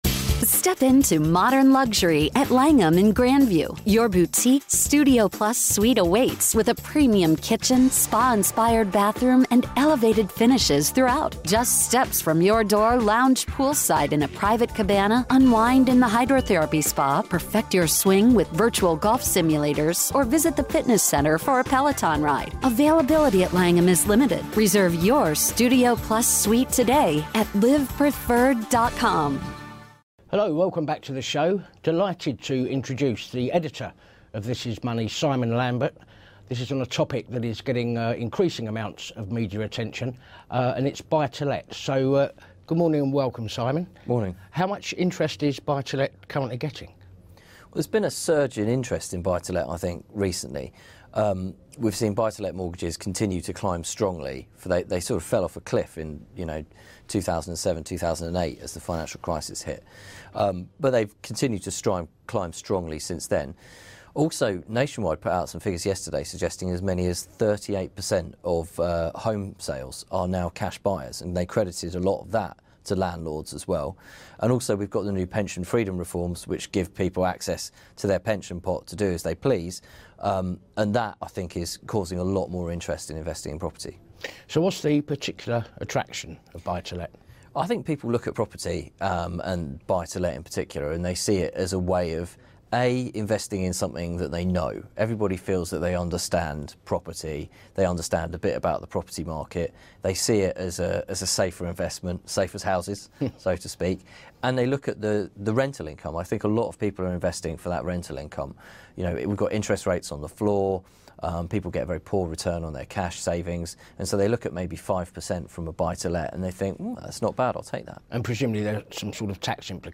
Housing discussion